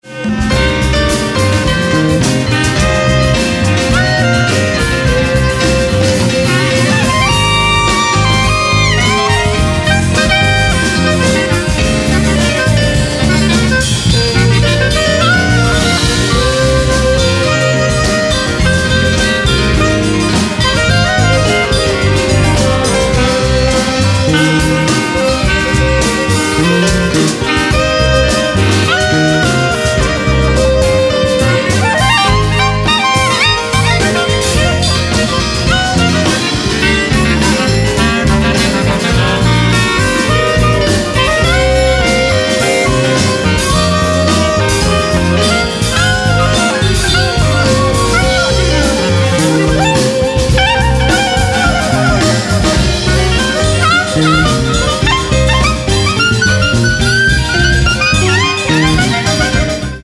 Live au FMPM 2006: